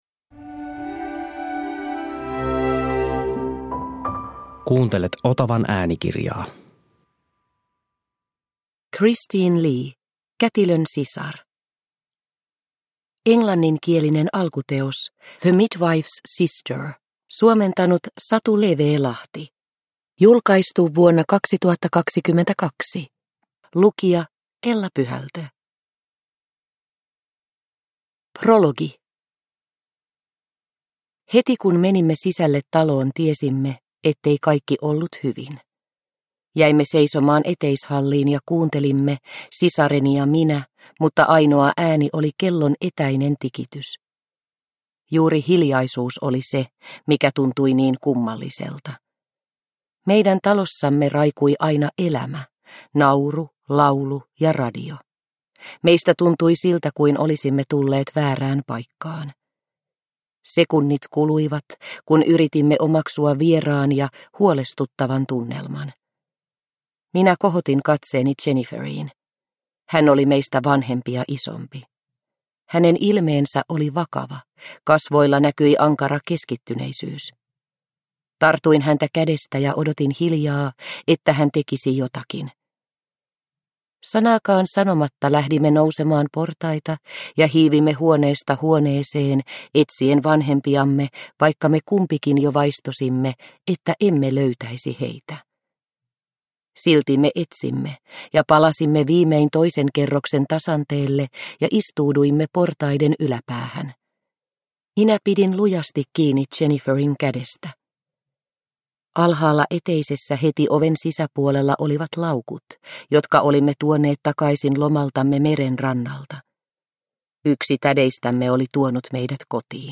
Kätilön sisar – Ljudbok – Laddas ner